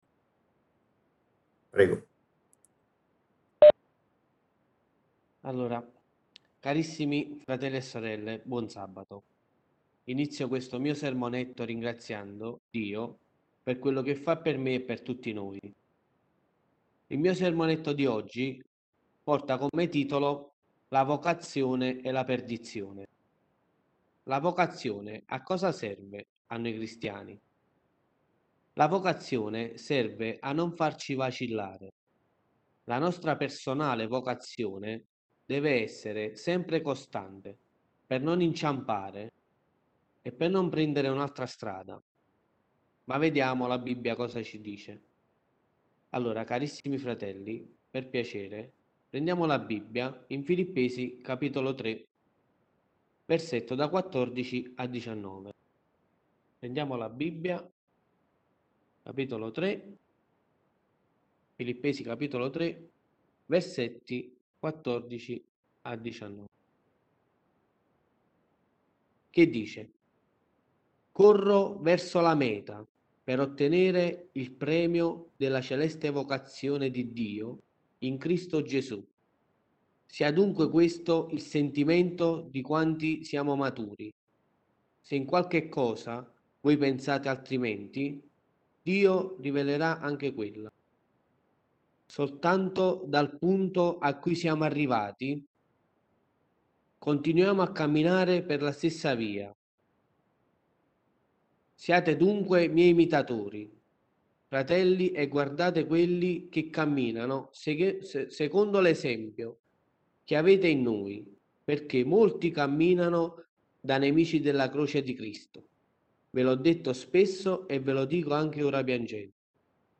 Sermonetto